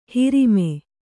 ♪ hirime